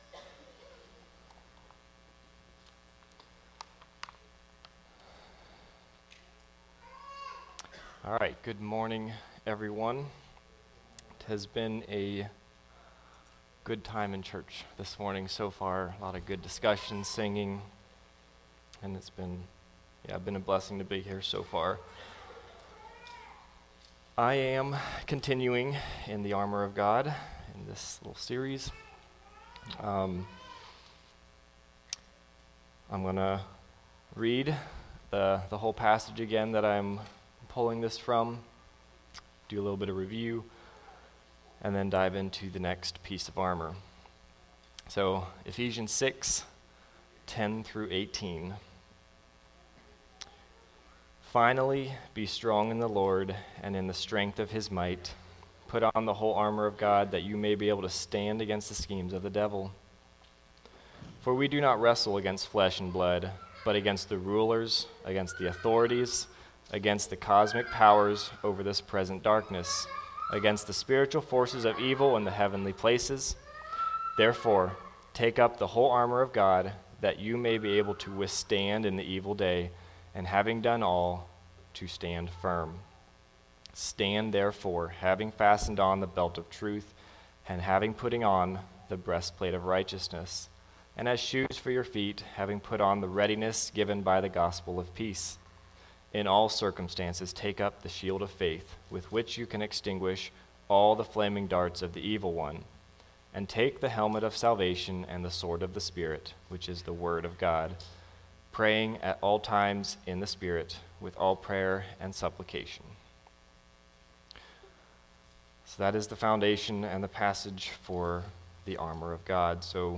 Series: Armor of God Service Type: Sunday Message